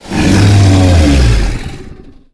Sfx_creature_snowstalker_distantcall_07.ogg